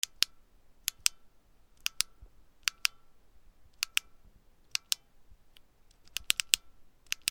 ボタン 懐中電灯
/ M｜他分類 / L10 ｜電化製品・機械